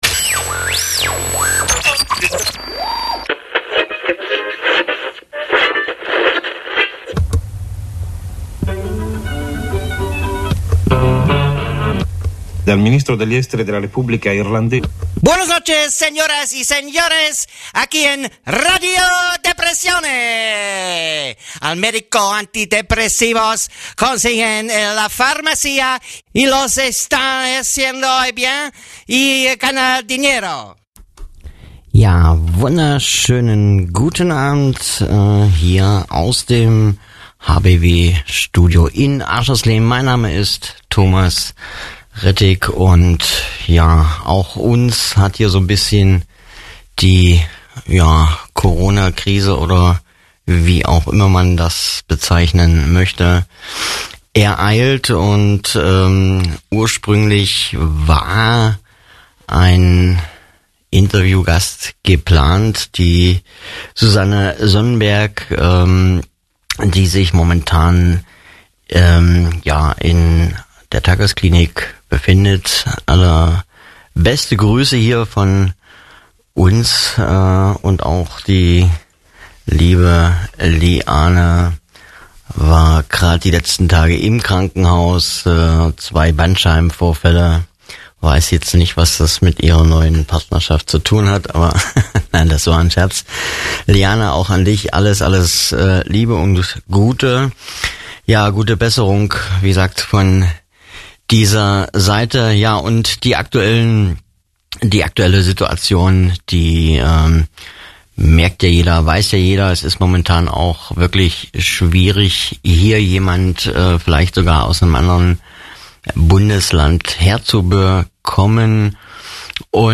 Es geht um psychische Störungen und Erkrankungen, Behandlungsmöglichkeiten und Anlaufpunkte für Betroffene. Dazu gibt es regelmäßig Interviews mit Fachleuten und Betroffenen, Buchtipps und Umfragen zu bestimmten Themen.